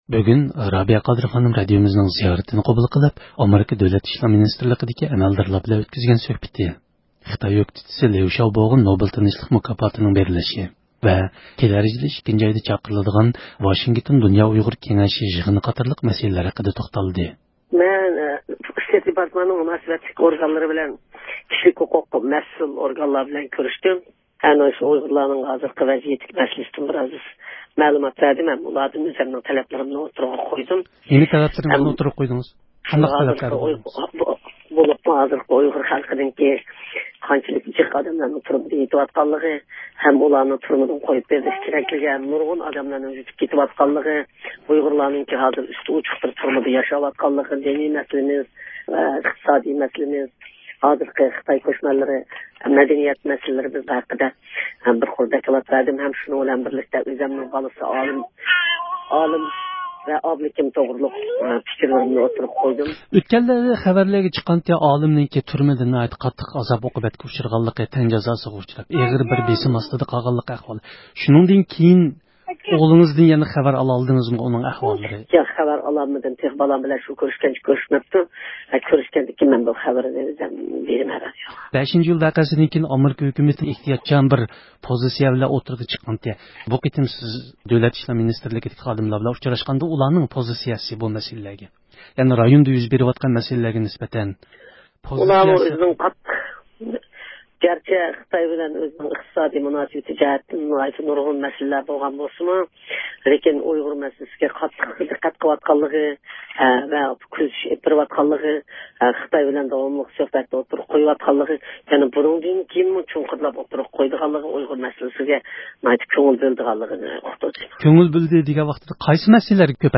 بۇ مۇناسىۋەت بىلەن رابىيە خانىم زىيارىتىمىزنى قوبۇل قىلىپ، بۇ ھەقتىكى سوئاللىرىمىزغا جاۋاب بەردى.